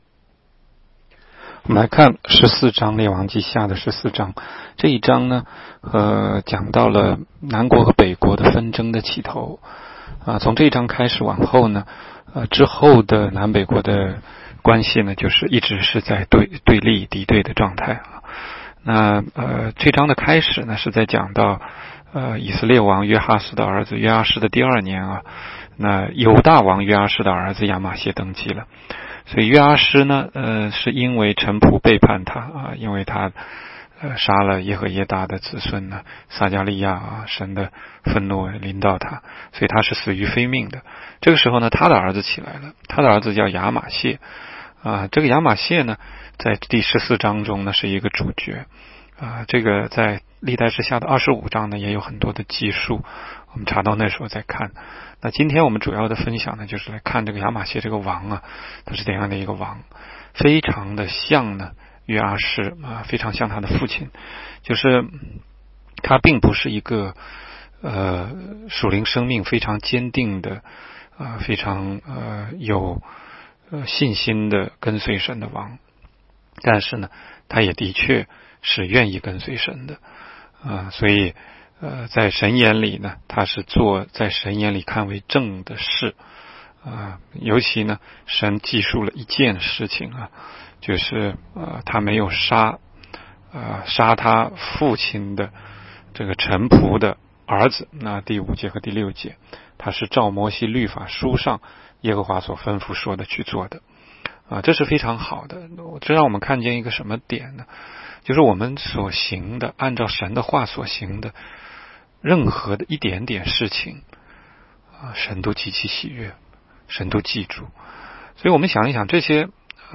16街讲道录音 - 每日读经-《列王纪下》14章